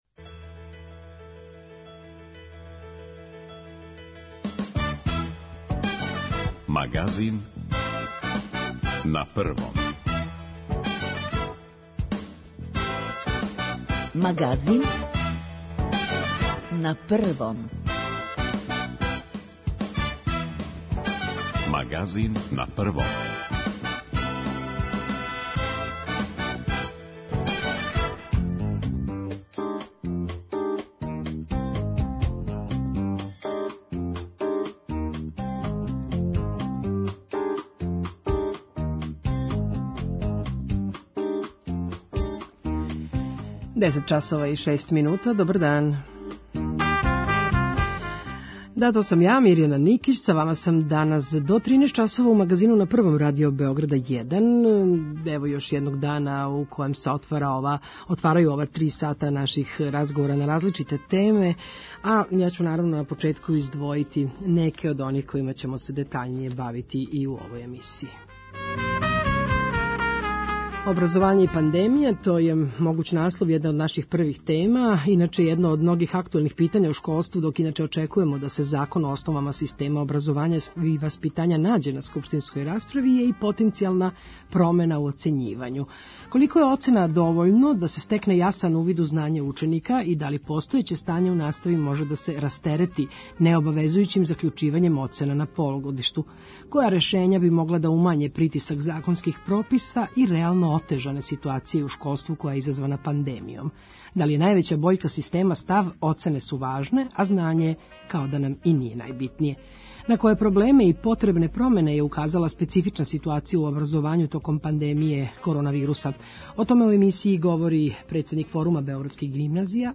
О томе шта би требало да се догоди да Асанж дочека слободу, разговарамо са новинарком Љиљаном Смајиловић.,,Сајбер булинг" је сваки облик вршњачког насиља у виртуелном свет